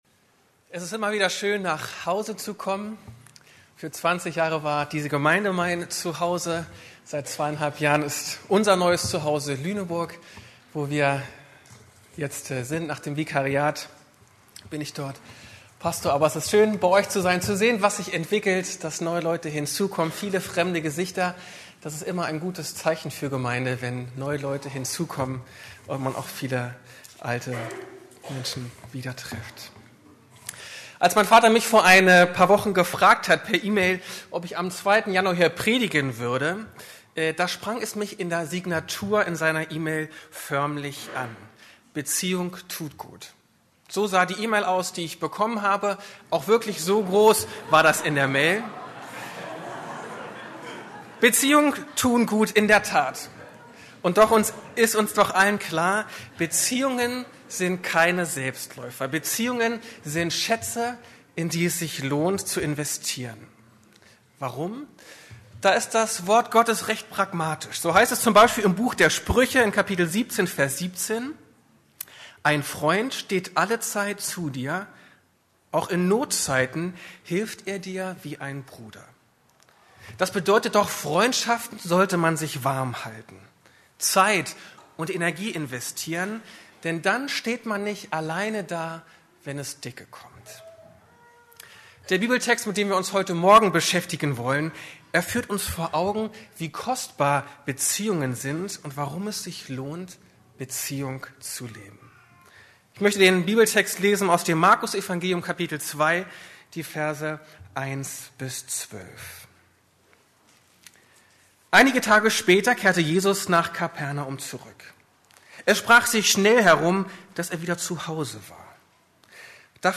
Tragende Gemeinschaft ~ Predigten der LUKAS GEMEINDE Podcast